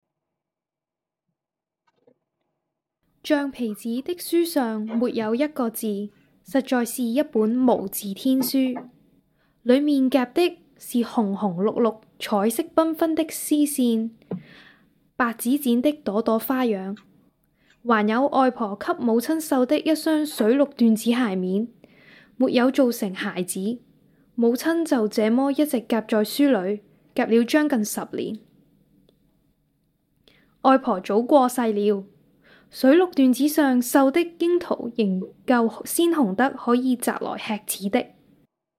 • 女粤04 香港粤语港式粤语女声 年轻 大气浑厚磁性|沉稳|娓娓道来|积极向上|时尚活力|神秘性感|亲切甜美|素人